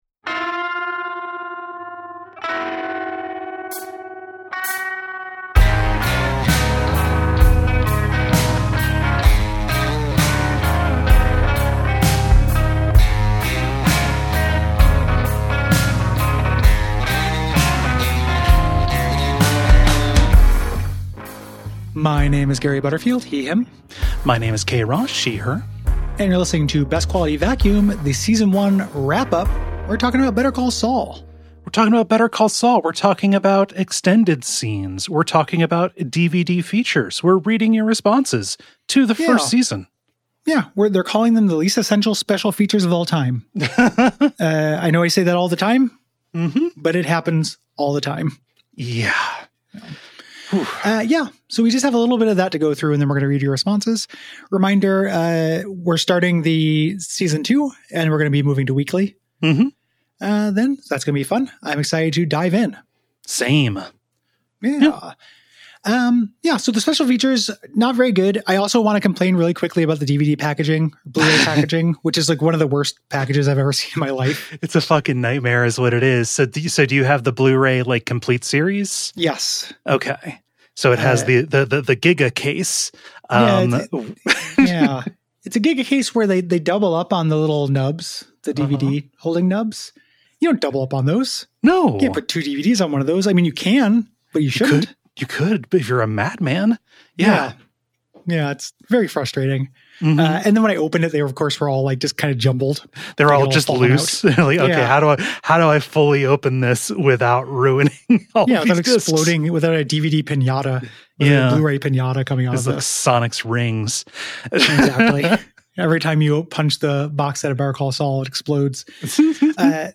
Author Kate Summerscale tells us the story at the center of her book, The Suspicions of Mr. Whicher.